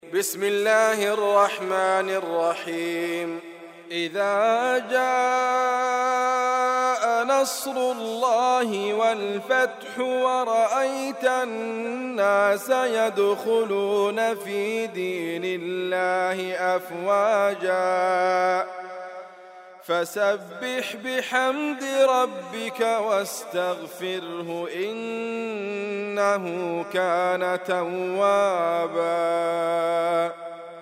القران الكريم -> ادريس ابكر -> النصر